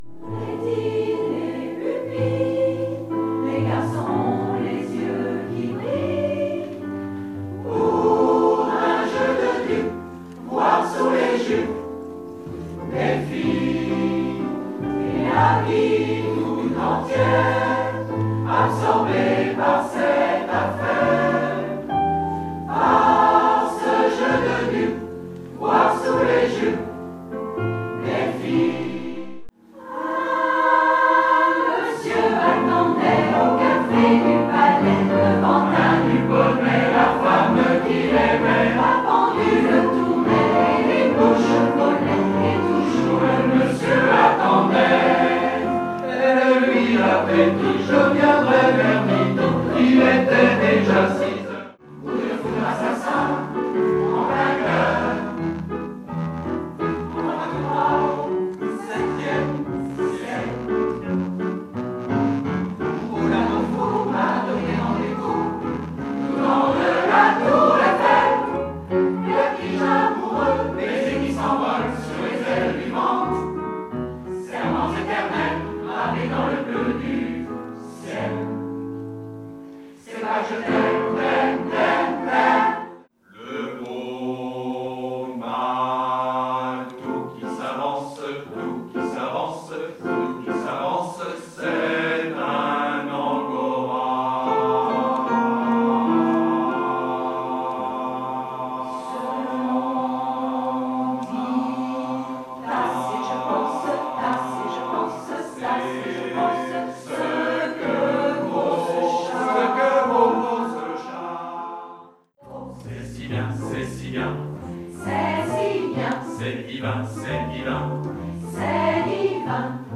Habillés tout de blanc ou tout de noir, nous avons chanté toute l’ambiguïté de la relation amoureuse. Du fantasme amoureux à la séparation, en passant par la rencontre ou la nostalgie, notre spectacle a brossé une palette des sentiments amoureux.